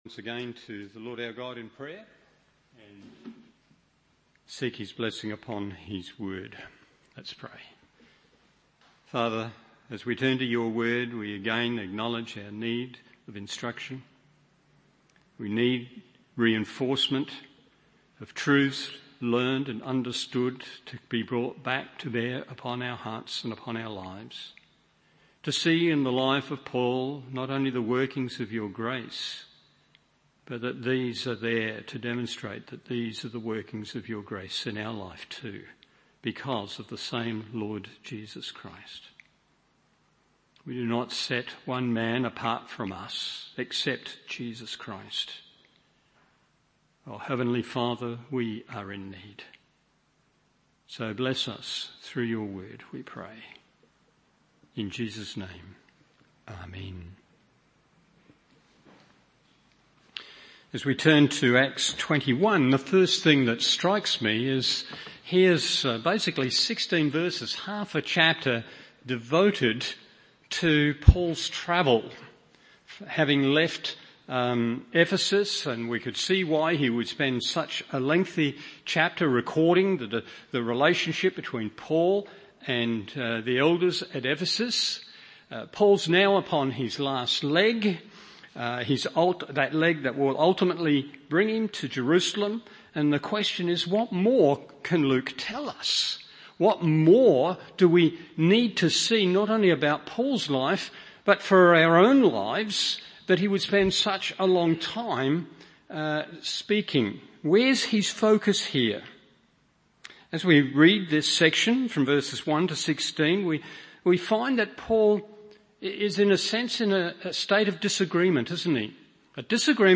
Evening Service Acts 21:1-16 1.